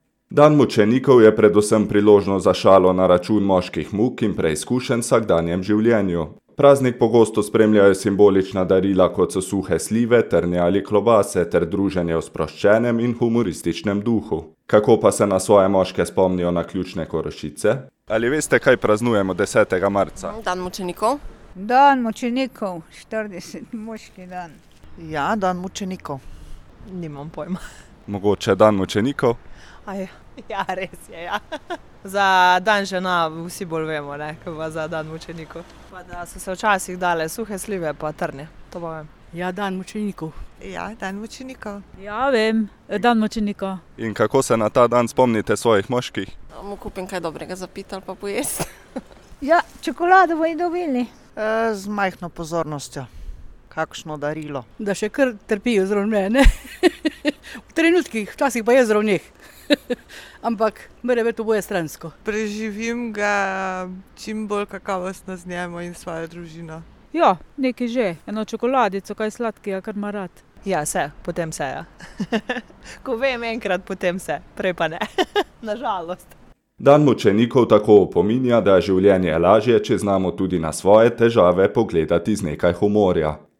Kako pa se na svoje moške spomnijo naključne Korošice?